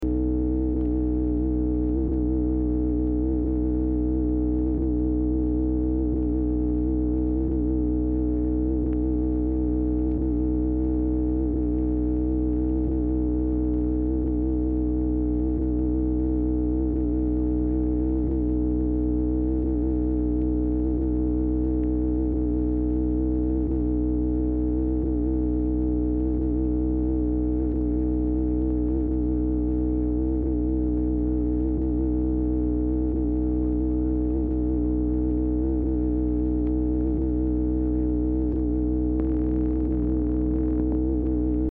Telephone conversation # 2100, sound recording, OFFICE NOISE, 2/17/1964, time unknown | Discover LBJ